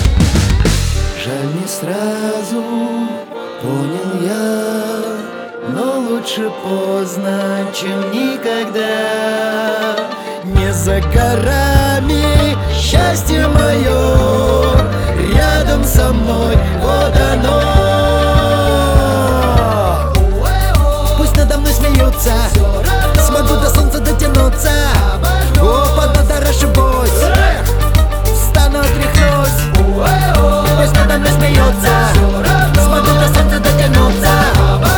Жанр: Русская поп-музыка / Рок / Русский рок / Русские